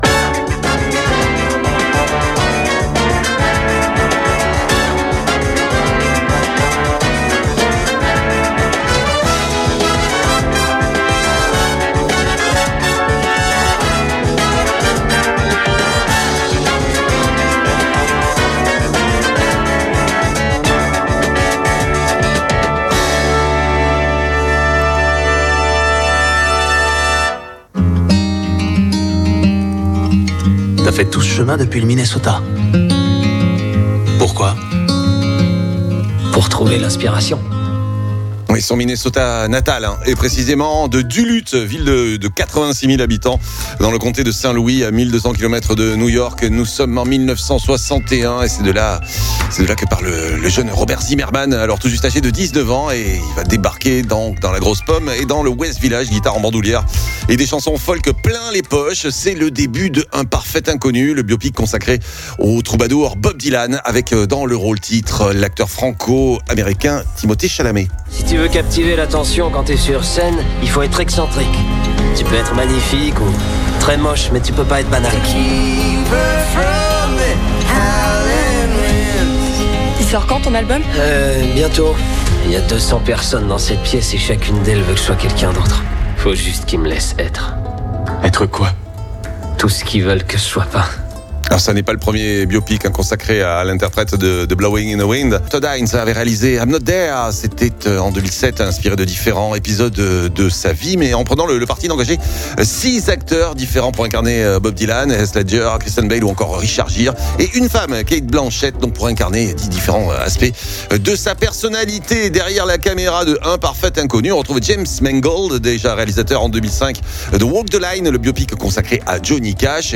« Un parfait inconnu » avec Timothée Chalamet dans le rôle du barde américain était projeté en avant première ce lundi soir au Cinéma le Grand Club à Mont de Marsan avant sa sortie officielle le 2 janvier prochain. Réactions à la sortie de la salle….